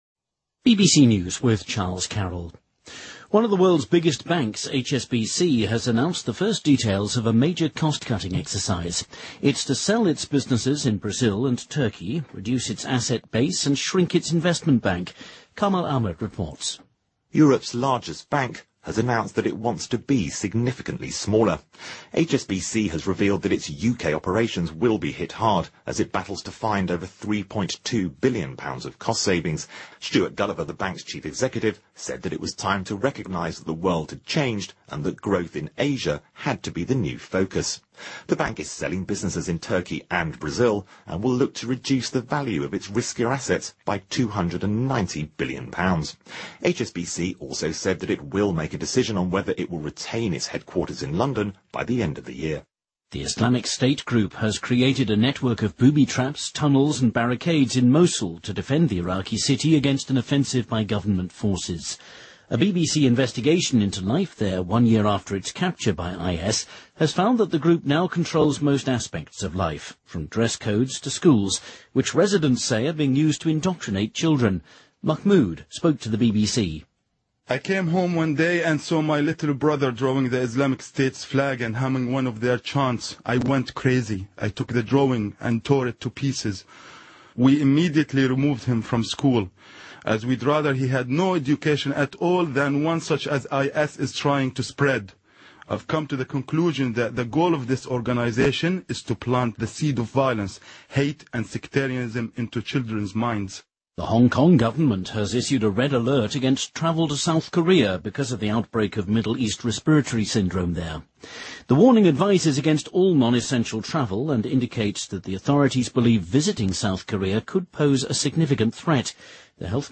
BBC news,汇丰银行大幅削减运营成本